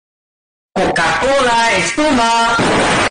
Coka Cola exploda earrape
More Sounds in Earrape Soundboard
coka-cola-exploda-earrape.mp3